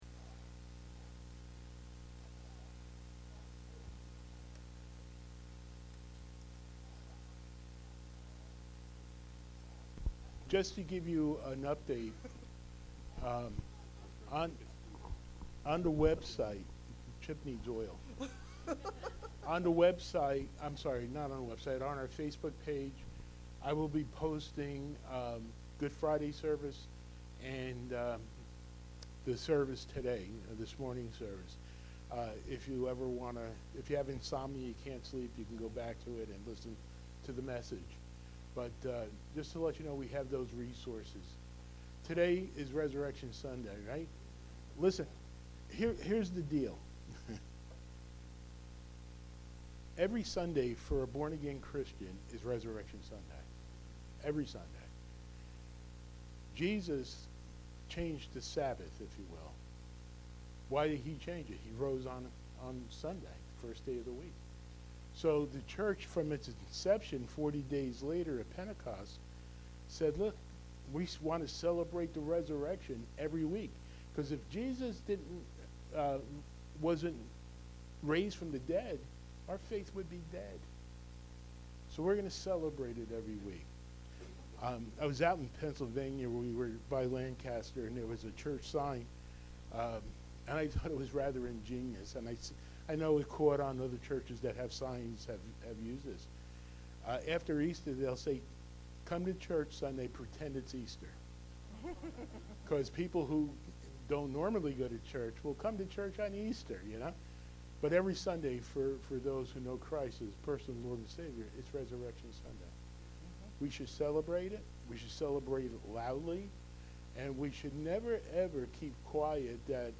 Listen to a powerful and encouraging Easter Service!